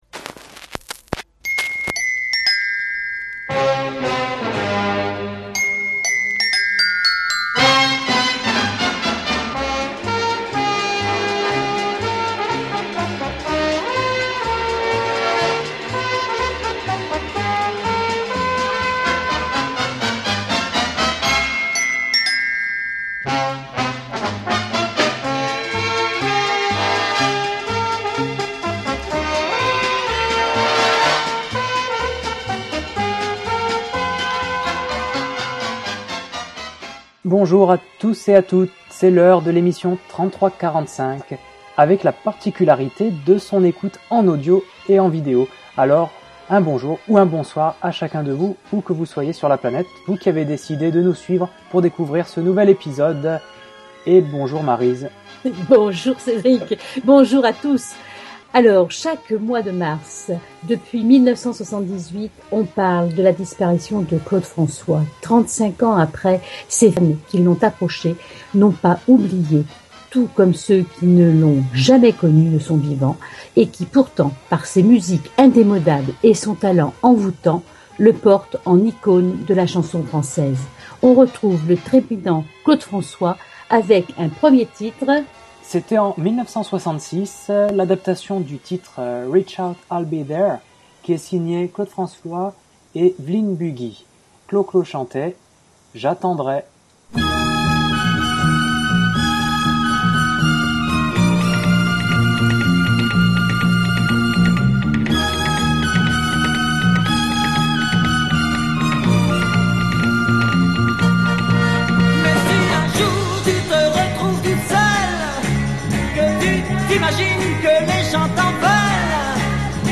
Le Podcast Journal, en partenariat avec Radio Fil, vous propose cette émission musicale dédiée aux années vinyles